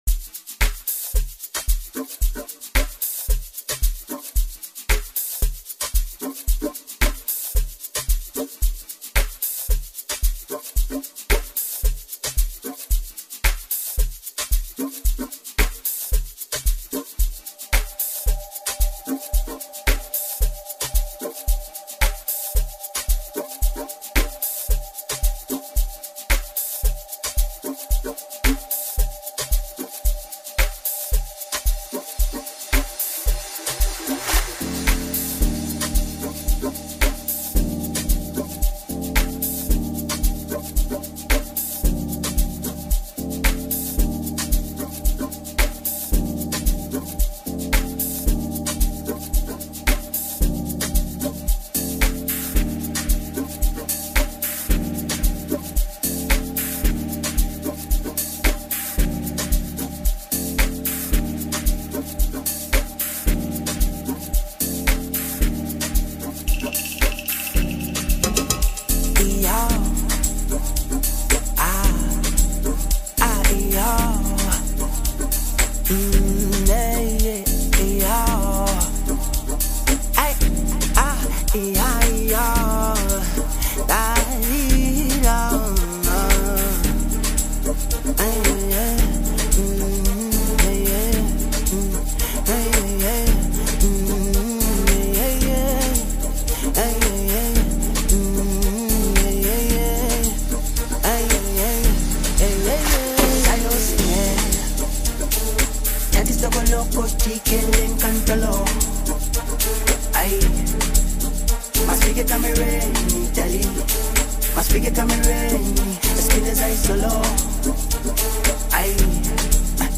A cool and relaxing music, Just for you.